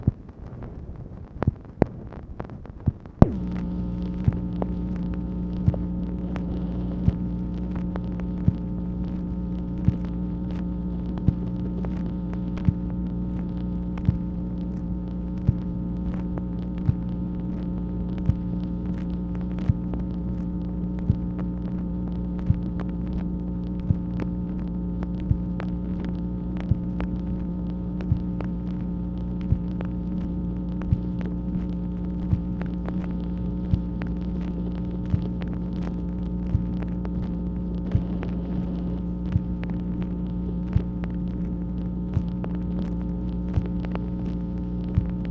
MACHINE NOISE, September 26, 1966
Secret White House Tapes | Lyndon B. Johnson Presidency